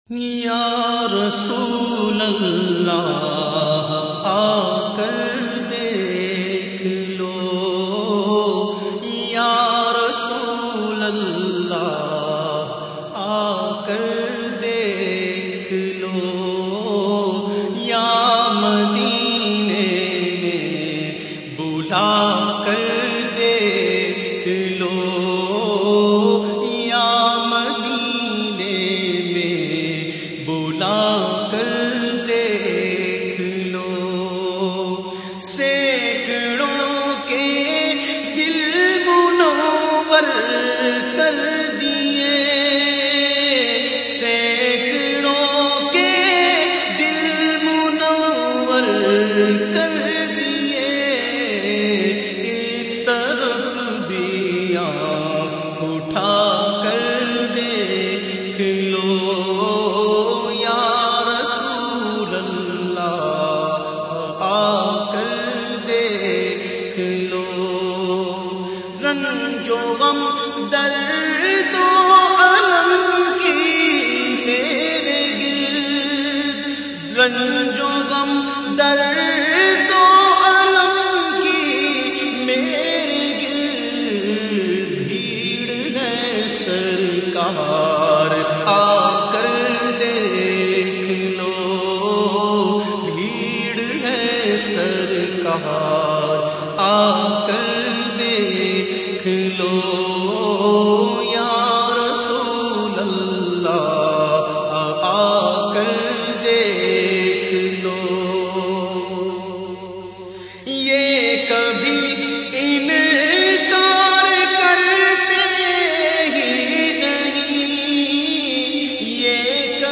The Naat Sharif Ya Rasool Allah Aakar Dekh Lo recited by famous Naat Khawan of Pakistan owaise qadri.